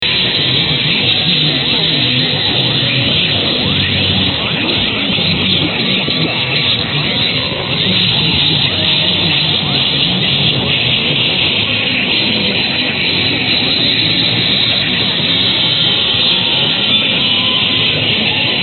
KCKM 1330 DX TEST MAKES IT TO SCOTLAND:
But after some struggling with the settings and using a very narrow bandwidth, I have a partial piece of code.
I have also included some weak sweepers.
140322_0526_sweepers_1330_kckm_dx_test.mp3